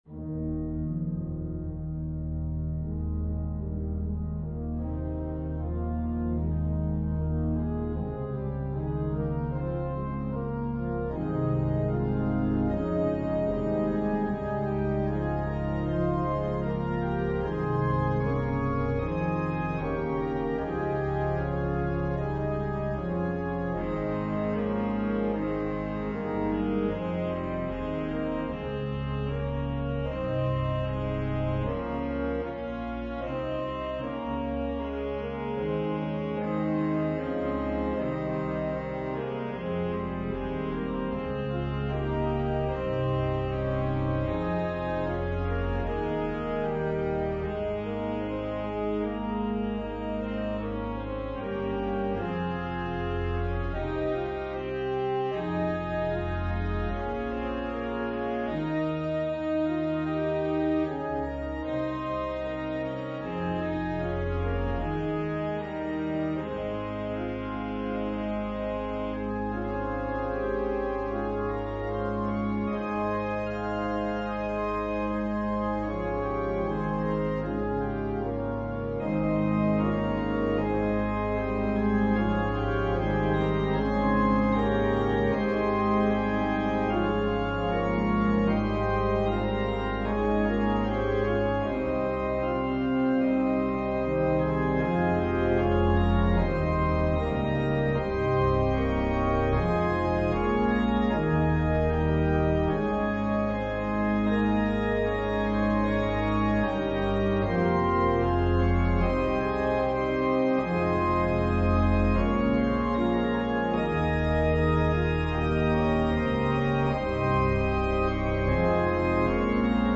An organ solo version of Mack Wilberg's choral arrangement.
Voicing/Instrumentation: Organ/Organ Accompaniment We also have other 14 arrangements of " Guide Us, Oh Thou Great Jehovah ".